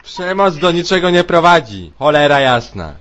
Worms speechbanks
bungee.wav